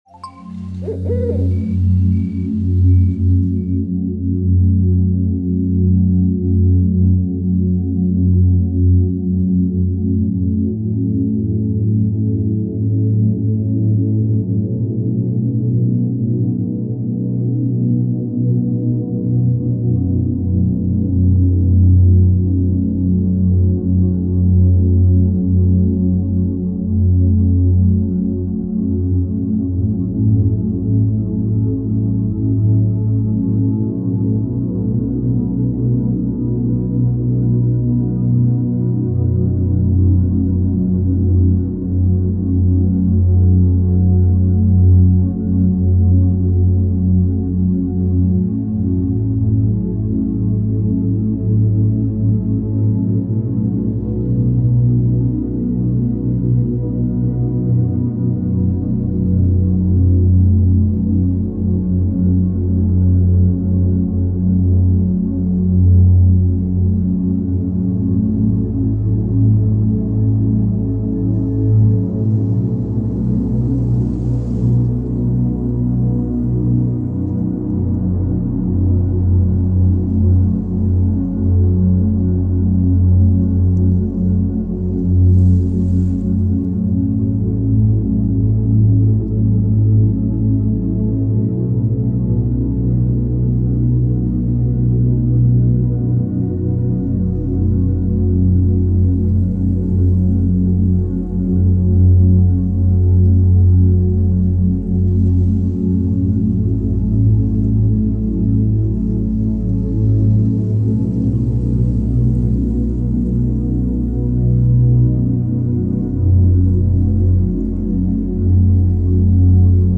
Juego de vibraciones para limpiar la mente, Relajar el cuerpo, Reconectar con la paz interior.